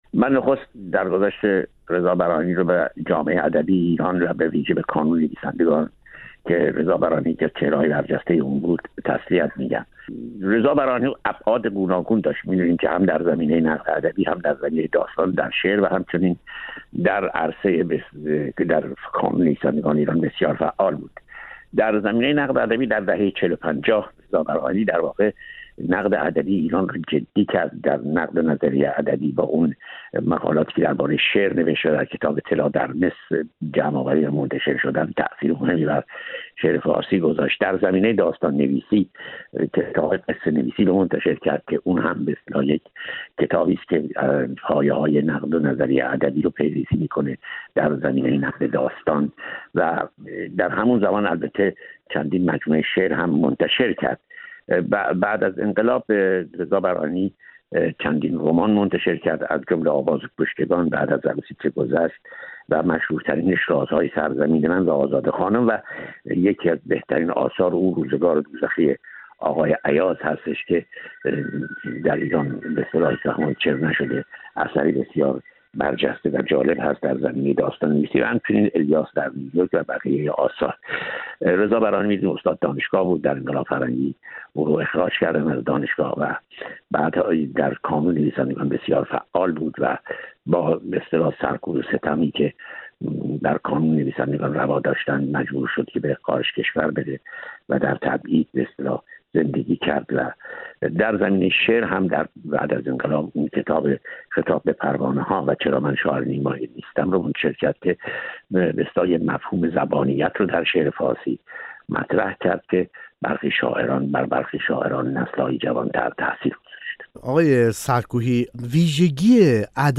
در گفت‌وگو با فرج سرکوهی منتقد ادبی به کارنامه رضا براهنی پرداختیم.